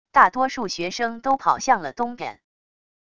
大多数学生都跑向了东边wav音频生成系统WAV Audio Player